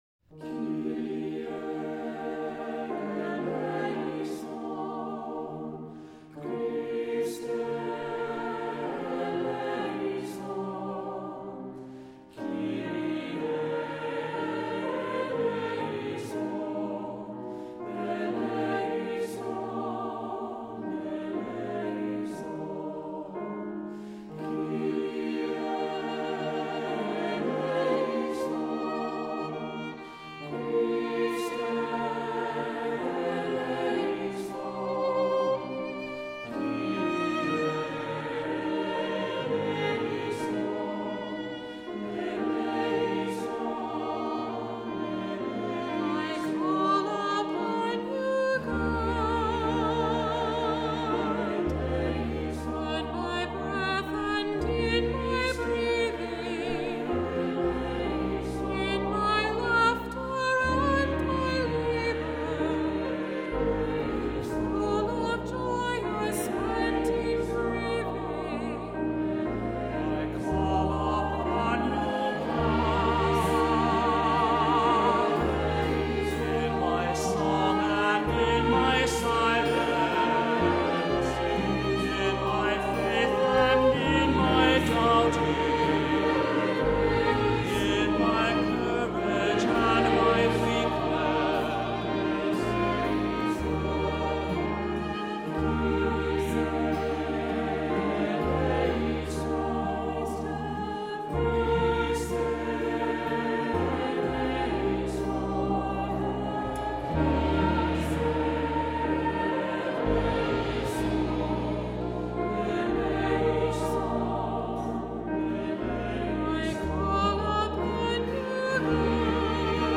Accompaniment:      Keyboard, C Instrument
Music Category:      Christian